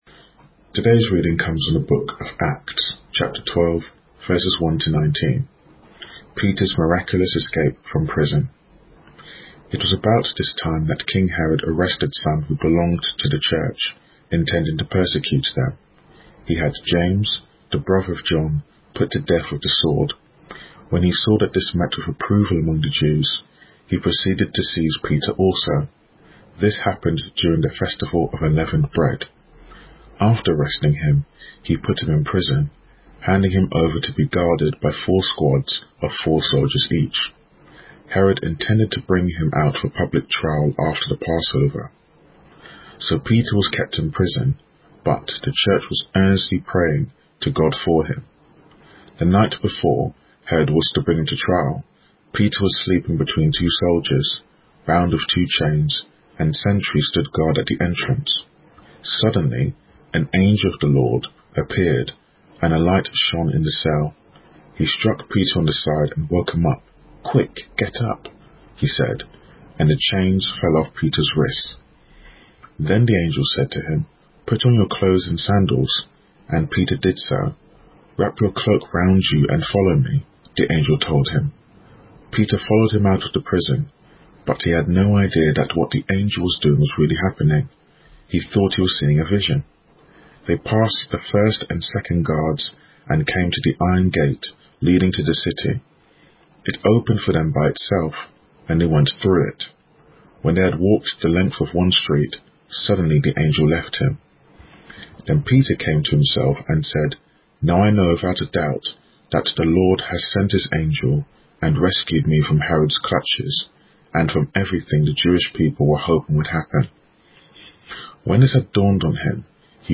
A message from the series "Culture Shift."